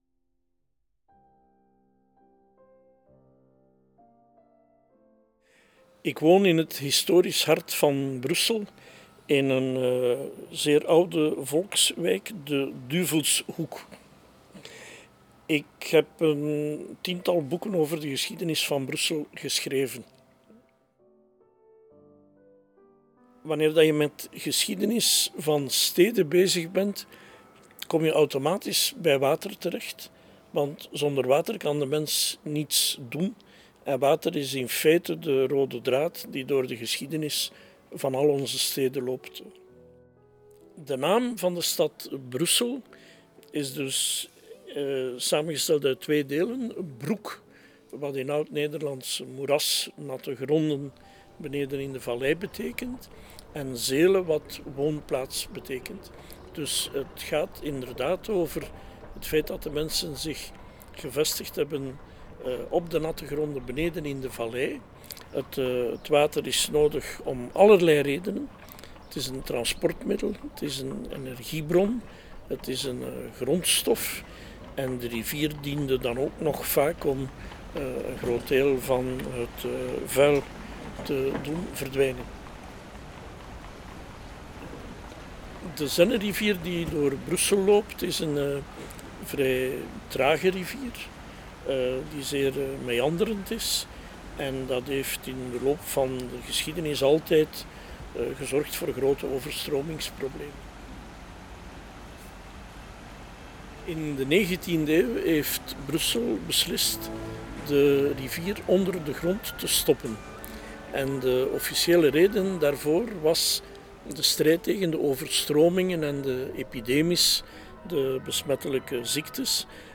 Cette balade sonore est un voyage dans les coulisses des métiers de l’eau à Bruxelles.